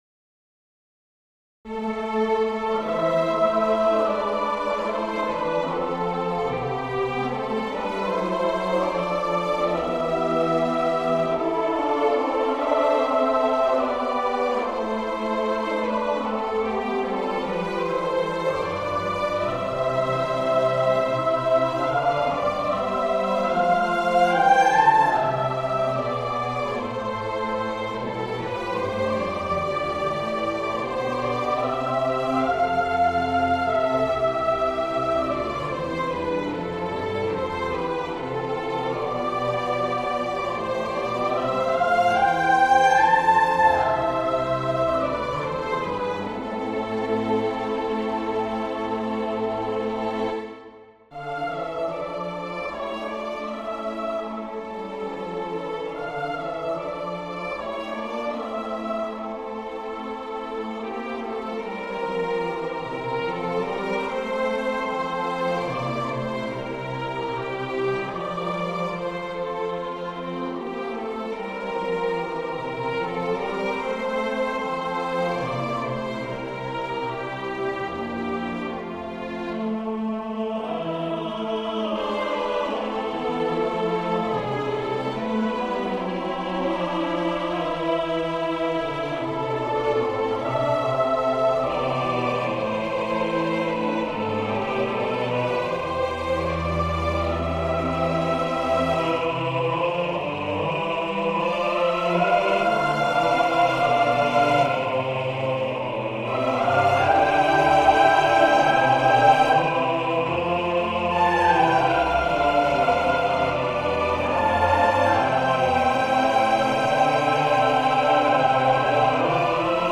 Another Christmas Mash-up - Choral, Vocal - Young Composers Music Forum